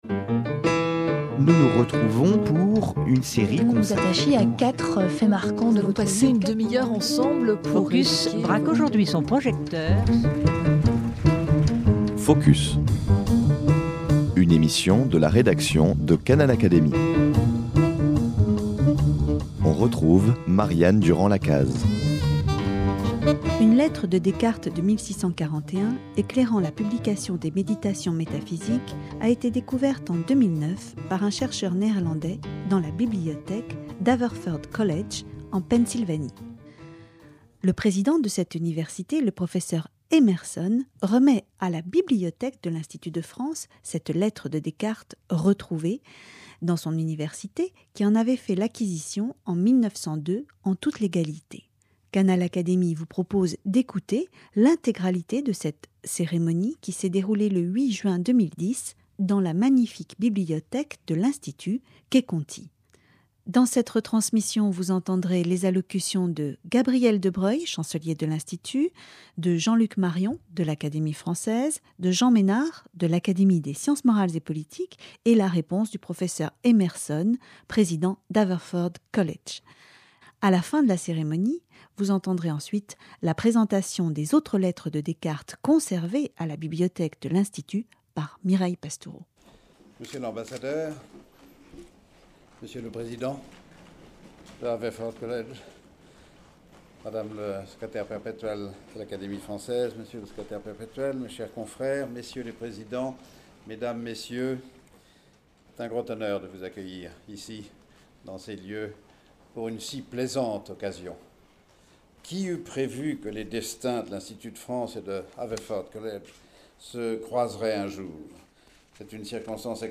Canal Académie vous propose d’écouter l’intégralité de cette cérémonie qui s’est déroulée, le 8 juin 2010, dans la magnifique Bibliothèque de l’Institut, quai Conti à Paris.